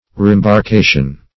Meaning of reembarkation. reembarkation synonyms, pronunciation, spelling and more from Free Dictionary.